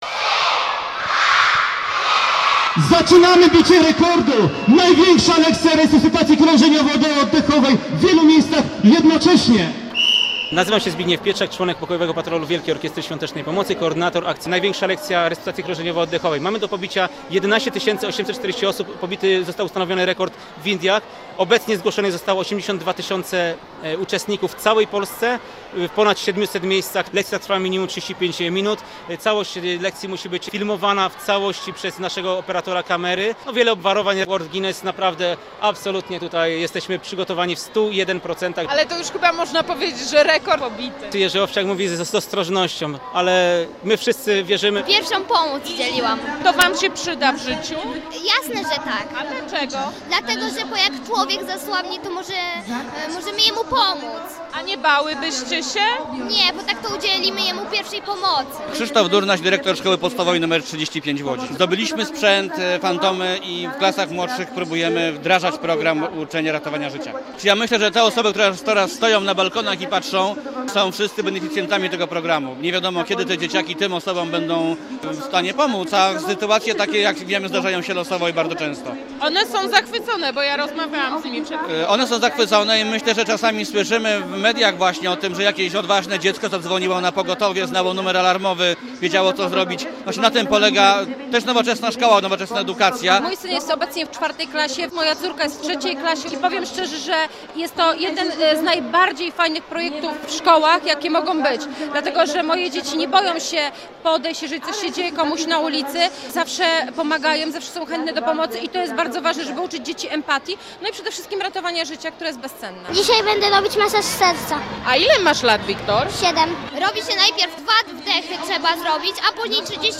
Posłuchaj relacji: Nazwa Plik Autor Dzieci biją rekord w jednoczesnej resuscytacji audio (m4a) audio (oga) ZDJĘCIA, NAGRANIA WIDEO, WIĘCEJ INFORMACJI Z ŁODZI I REGIONU ZNAJDZIESZ W DZIALE “WIADOMOŚCI”.